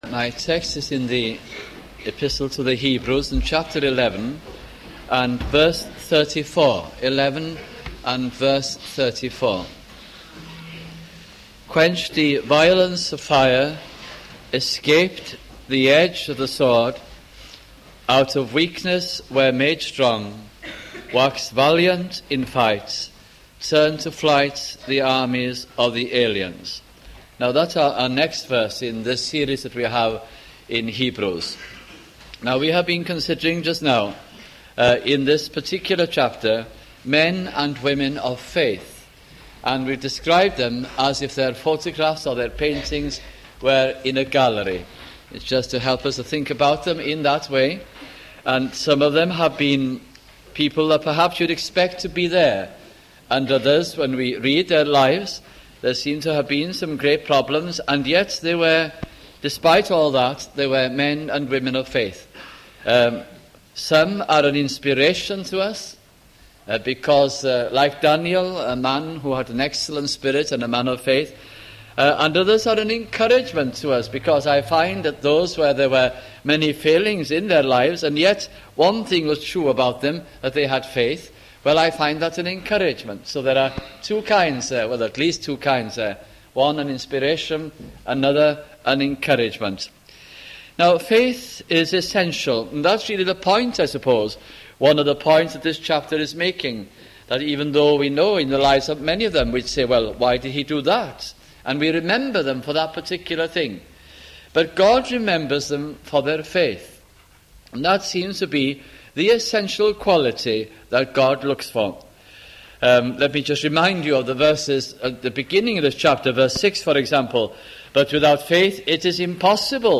» The Epistle to the Hebrews 1984 - 1986 » sunday morning messages